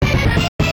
jingles-hit_02.ogg